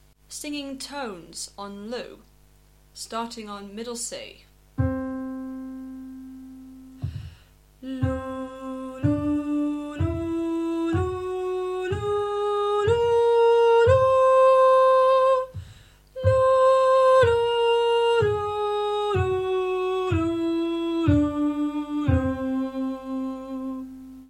Examples of singing a scale of semitones (also known as a chromatic scale) and singing a scale of tones can be found below:
Exercise: Singing Semitones
Semitones-vs-tones-2.mp3